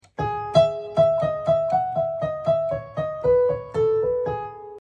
Piano Tutorial